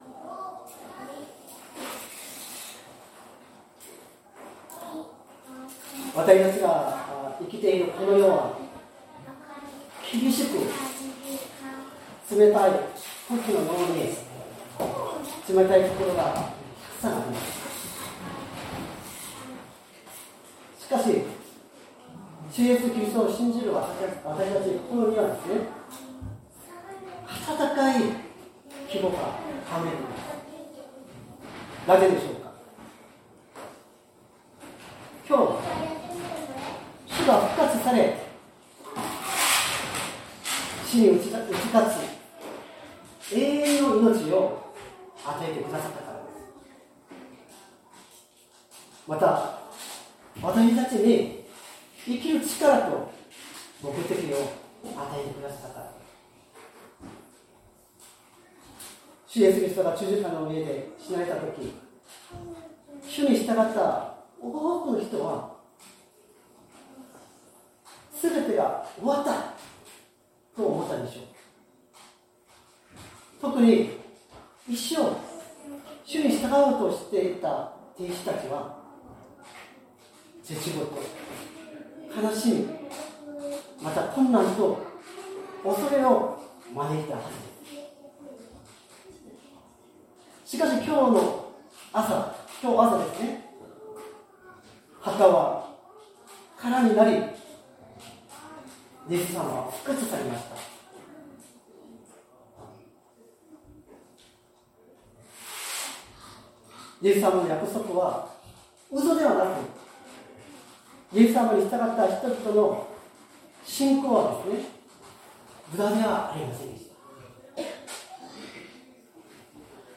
2025年04月20日朝の礼拝「復活のイエス様」善通寺教会
音声ファイル 礼拝説教を録音した音声ファイルを公開しています。